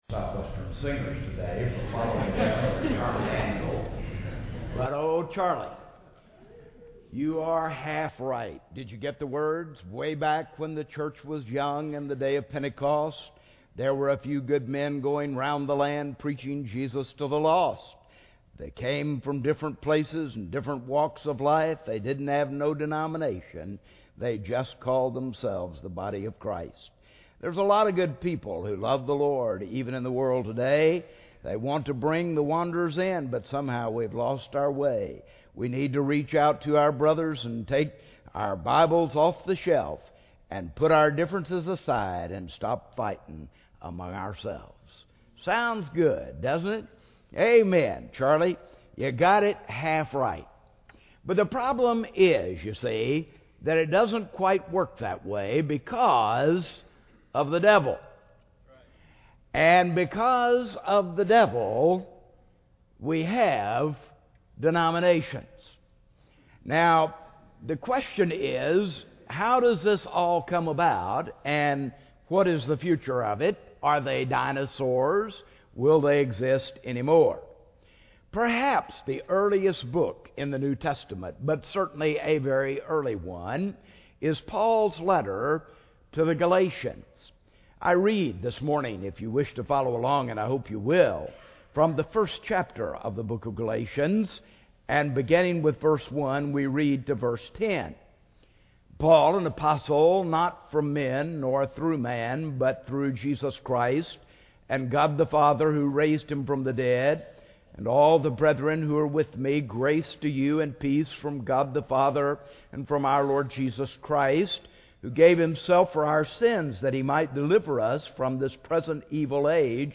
speaking on Galatians 1:1-10 in SWBTS Chapel on Wednesday March 25, 2009